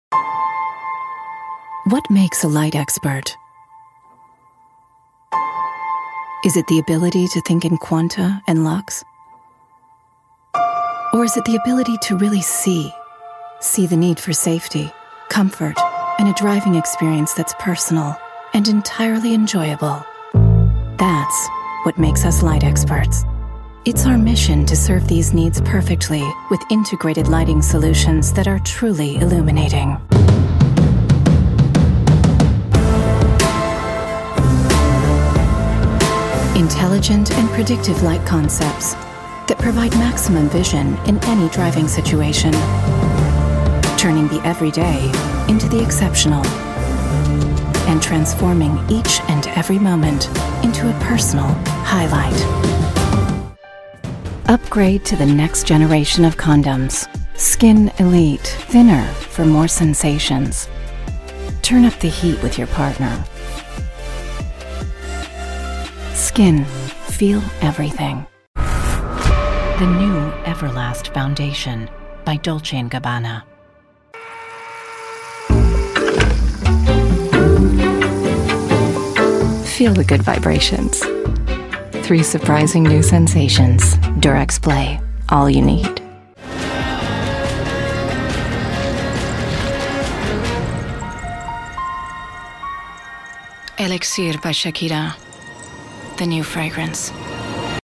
Commercial Smart, Sexy, Sensual Demo